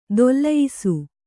♪ dollayisu